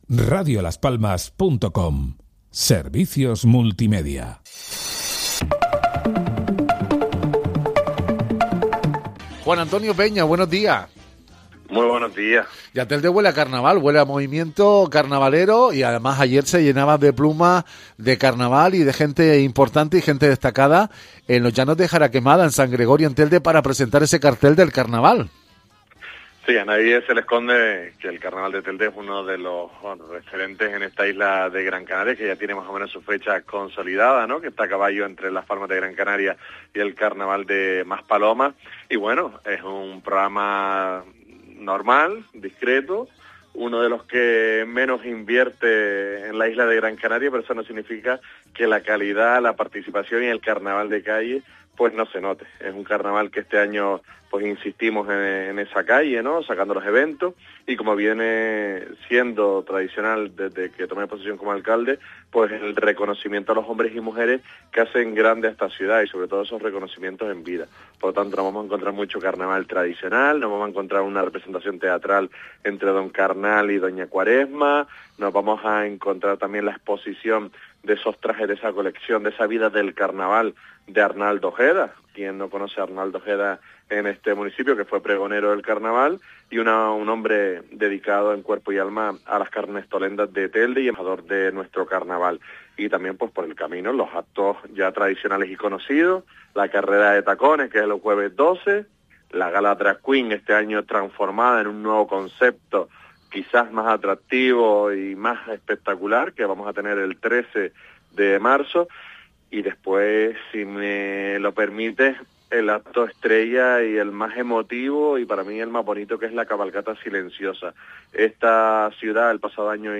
El alcalde de Telde, Juan Antonio Peña, aborda asfaltado, limpieza, deporte base y Carnaval en entrevista para Radio Las Palmas.